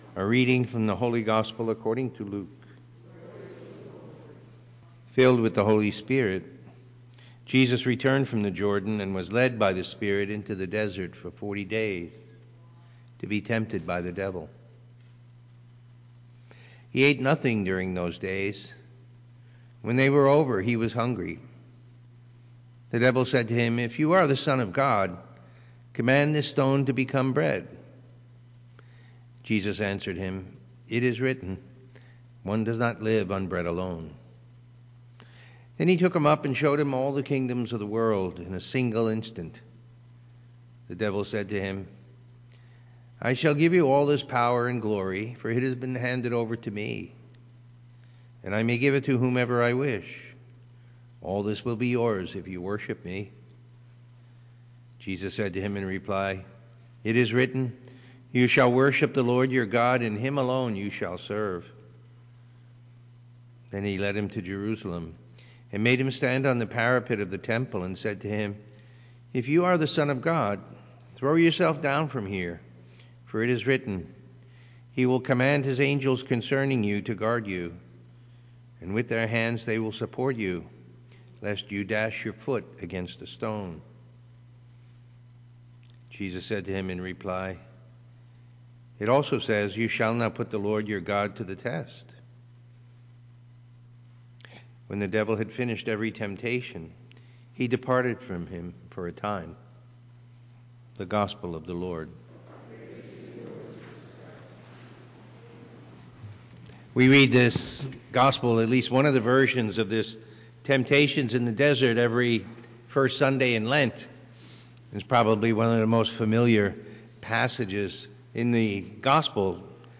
Listen to the homily from the Sunday Mass and meditate on the Word of God.